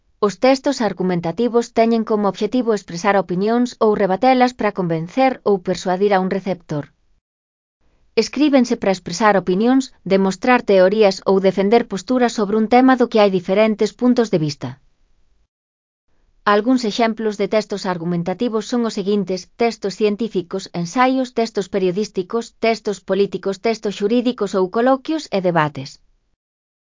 Lectura facilitada
Elaboración propia (Proxecto cREAgal) con apoio de IA, voz sintética xerada co modelo Celtia.. Características dos textos argumentativos. (CC BY-NC-SA)